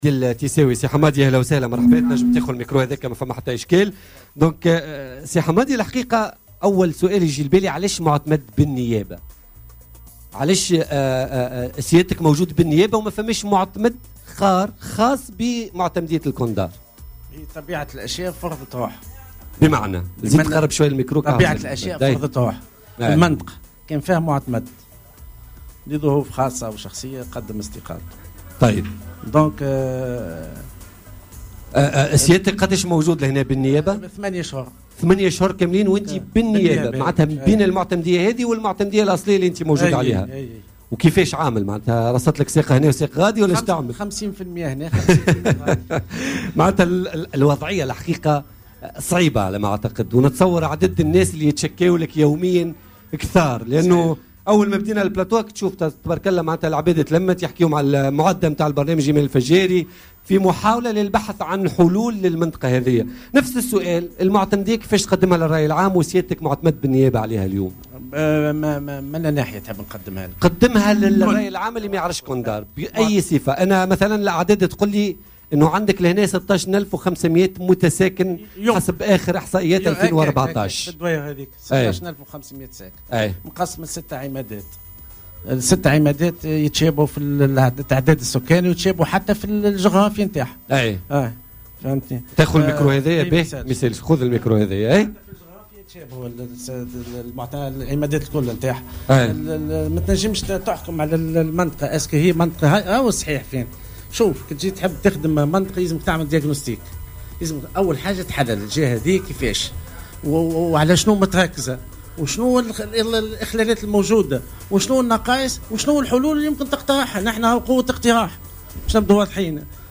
Hamadi Tissaoui, délégué de Kondar (gouvernorat de Sousse), est revenu ce jeudi 28 septembre 2017 lors de son passage dans l’émission Politica, sur la situation générale de la région.